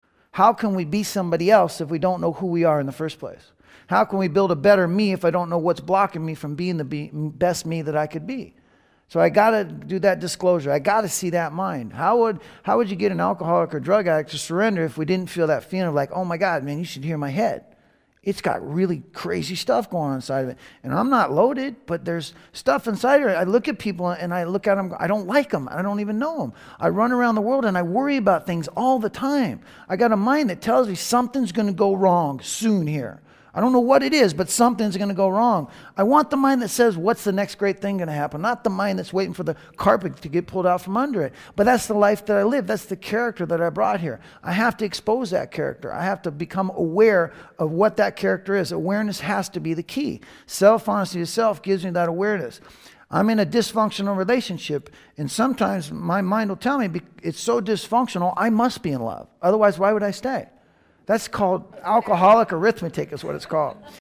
A group discussion on how living without spiritual and emotional anchors leads to instability, confusion, and disconnection and how applying core values restores clarity and faith.\n\n\n
This audio archive is a compilation of many years of lecturing.